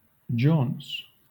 Ääntäminen
Ääntäminen Southern England: IPA : /d͡ʒɔːns/ Haettu sana löytyi näillä lähdekielillä: englanti Käännöksiä ei löytynyt valitulle kohdekielelle.